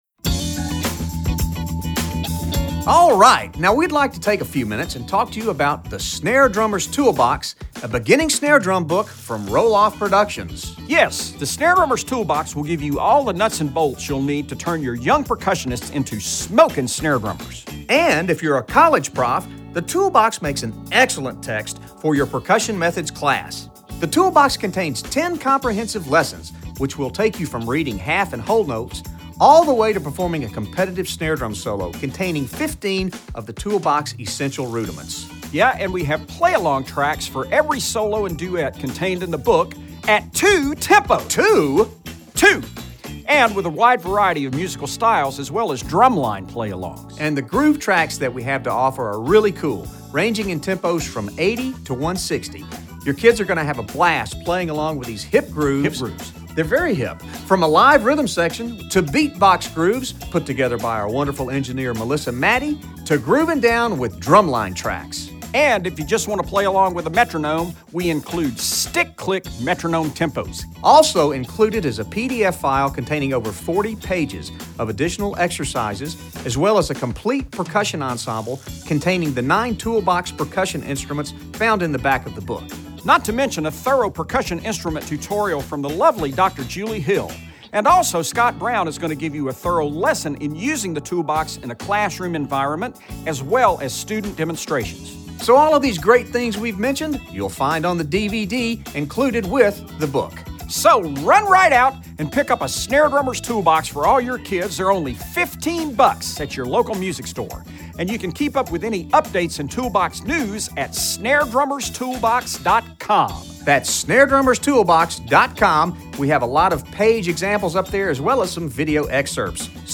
Voicing: Snare Drum Method